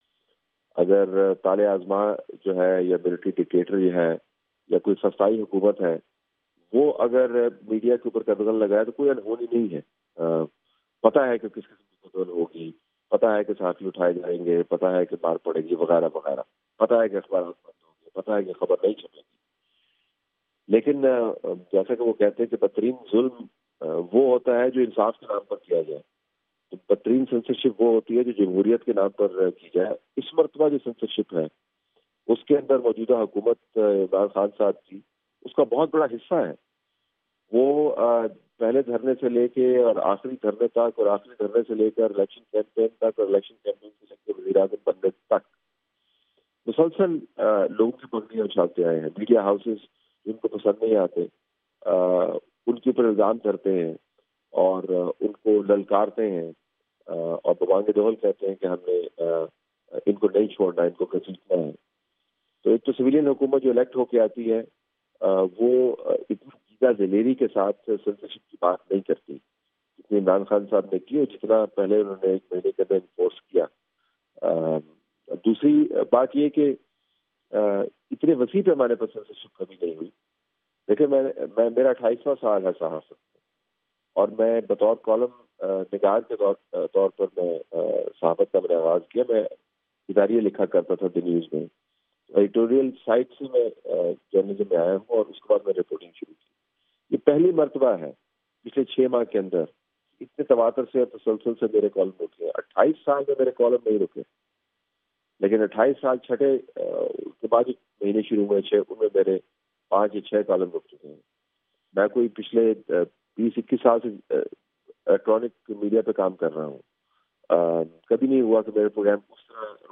Talat Hussain Interview